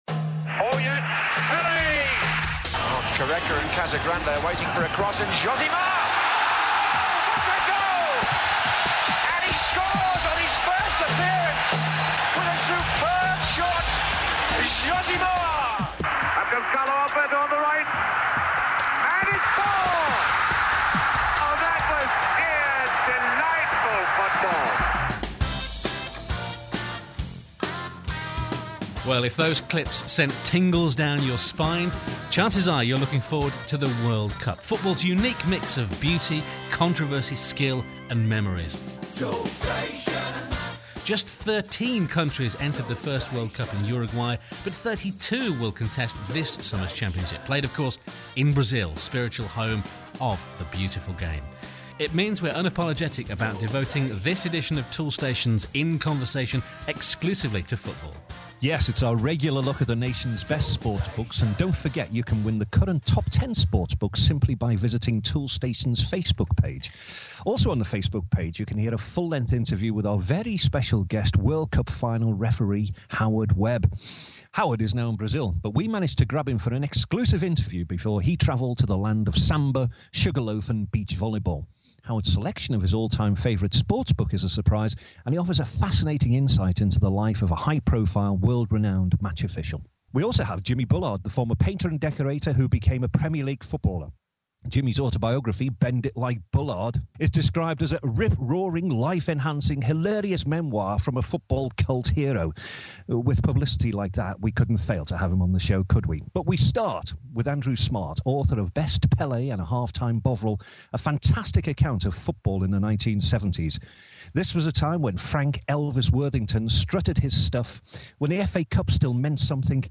Sports chat